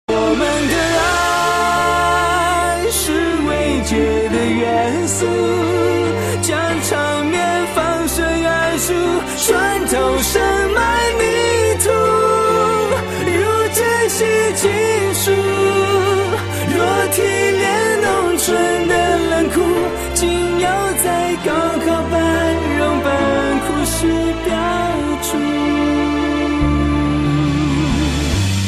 M4R铃声, MP3铃声, 华语歌曲 93 首发日期：2018-05-15 09:16 星期二